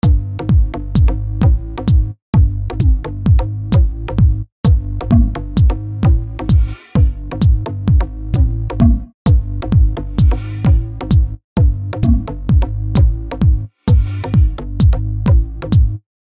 • Качество: 160, Stereo
электронные